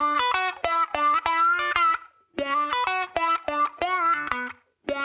Index of /musicradar/sampled-funk-soul-samples/95bpm/Guitar
SSF_StratGuitarProc2_95B.wav